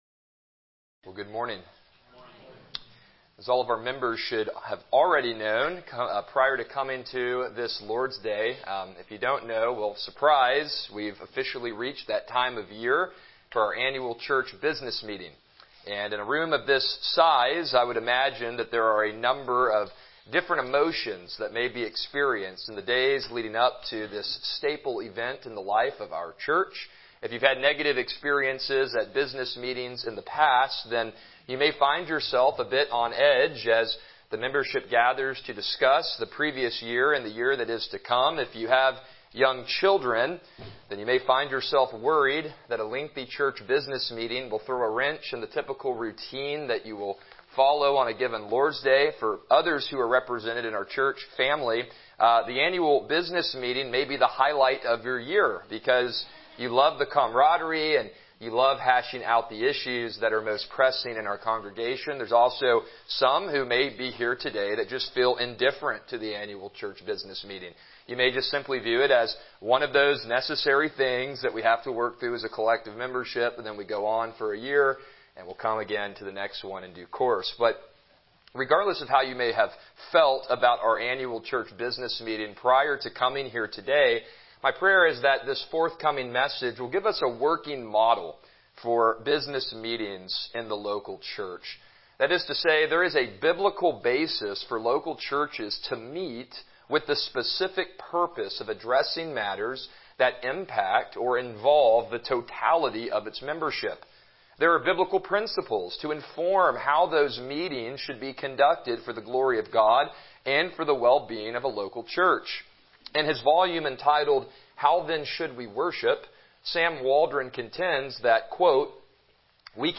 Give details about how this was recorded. Passage: Acts 15:1-29 Service Type: Morning Worship